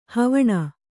♪ havaṇa